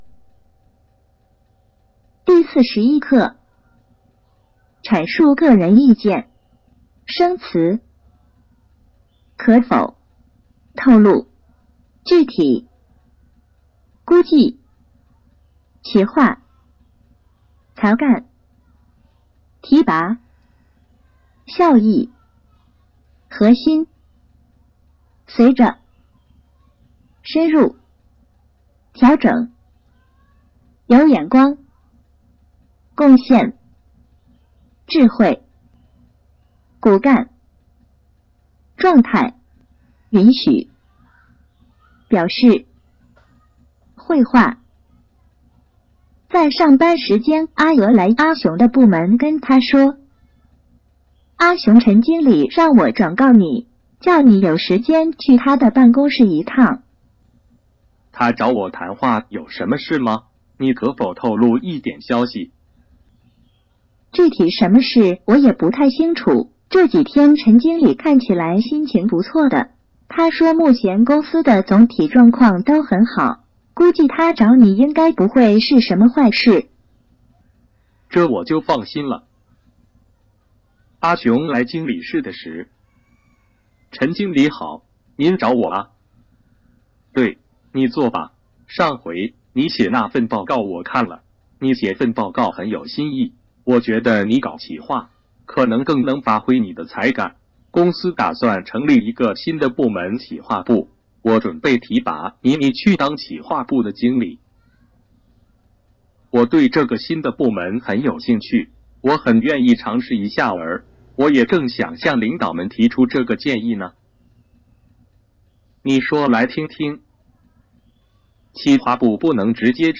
Thể loại: 朗读女语言合成声音.